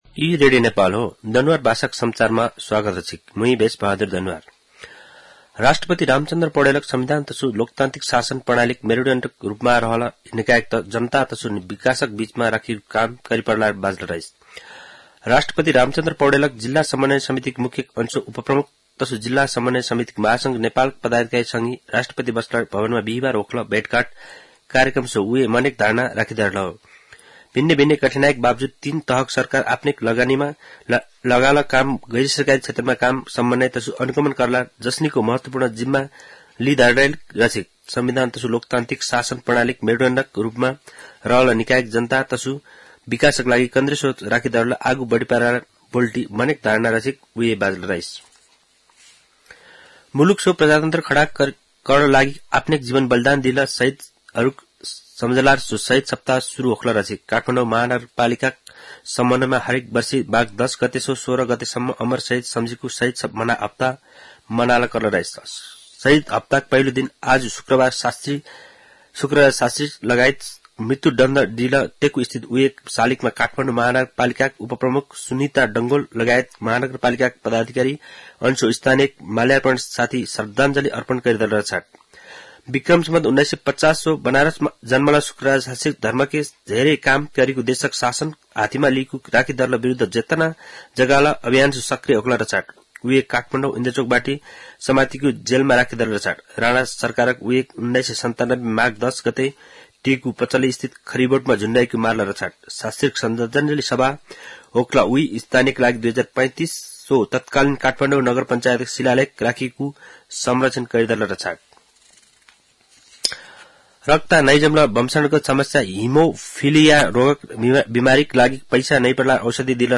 दनुवार भाषामा समाचार : ११ माघ , २०८१
Danuwar-news-1-5.mp3